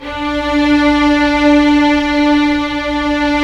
Index of /90_sSampleCDs/Roland LCDP13 String Sections/STR_Symphonic/STR_Symph.+attak